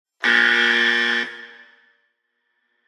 sfx_buzzer_bucked_off_moment.opus